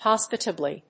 音節hos･pi･ta･bly発音記号・読み方hɑ́spɪtəbli|hɔ́s-
hospitably.mp3